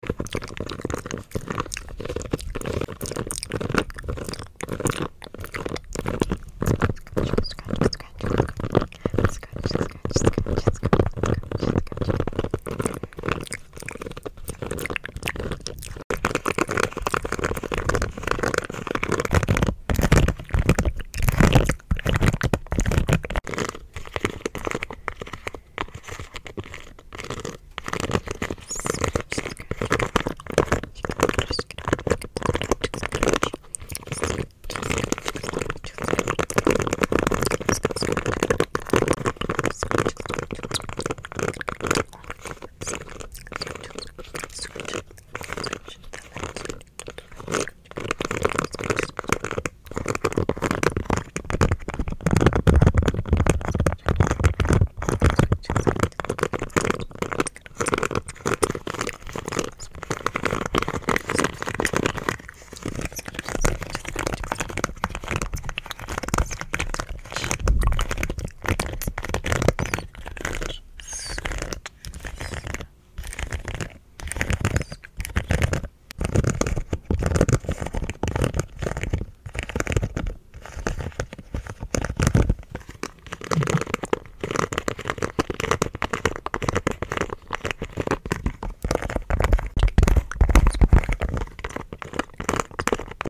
Scratching Pop